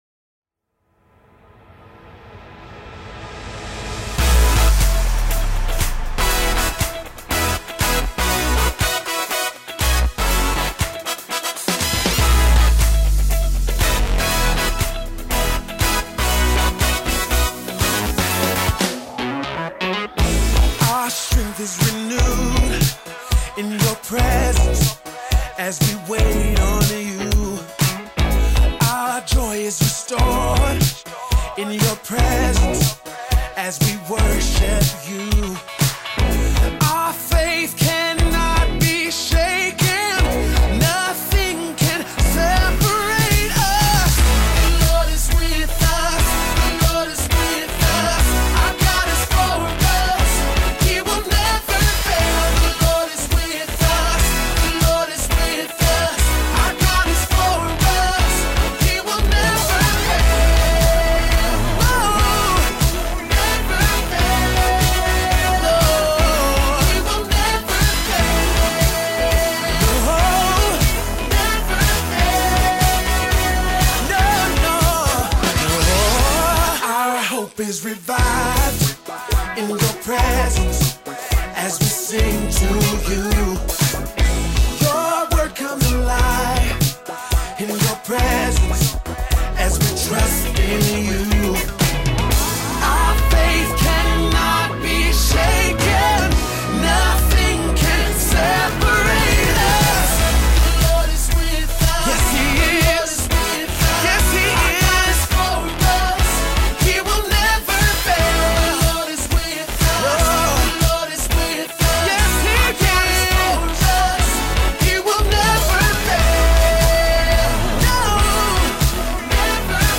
passionate, versatile voice
gospel music